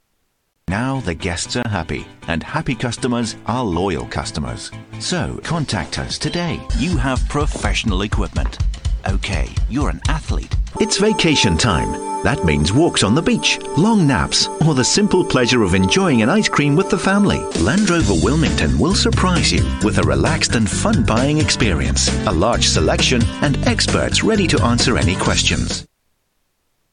外籍英式英语